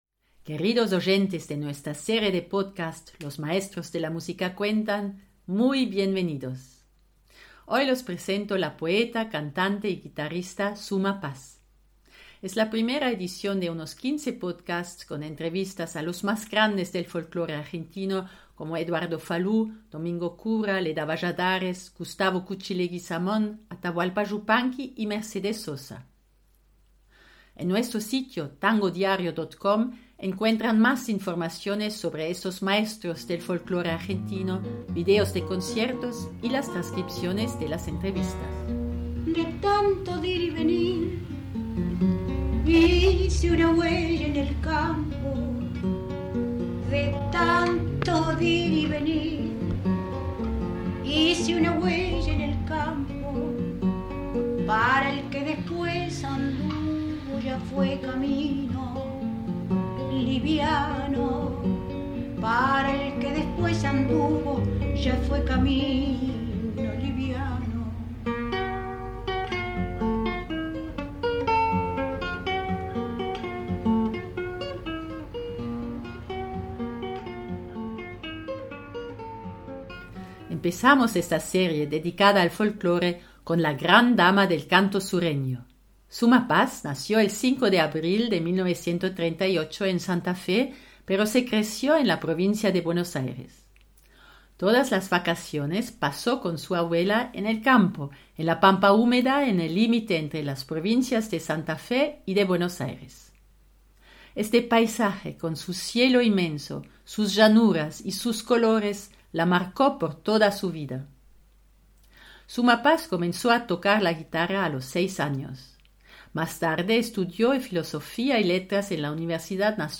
Suma Paz, the interview